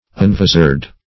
Search Result for " unvisard" : The Collaborative International Dictionary of English v.0.48: Unvisard \Un*vis"ard\, v. t. [1st pref. un- + vizard.]